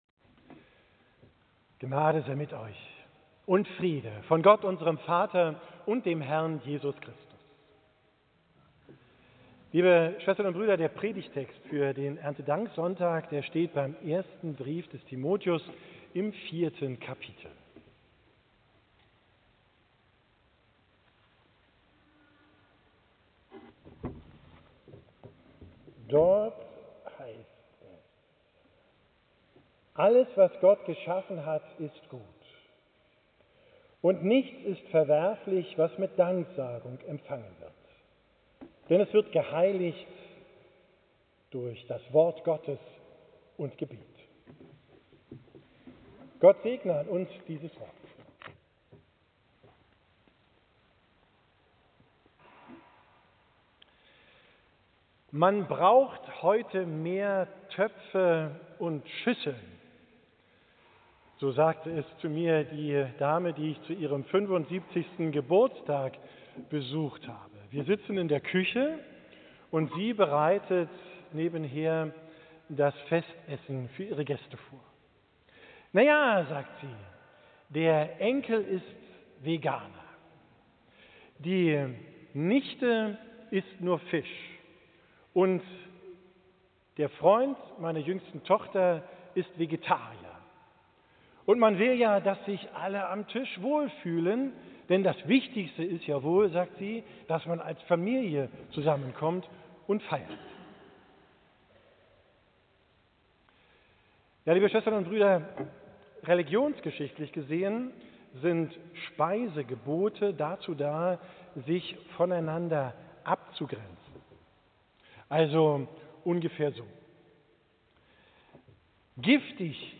Predigt vom Sonntag Erntedank, 6.